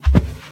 minecraft / sounds / mob / cow / step4.ogg
step4.ogg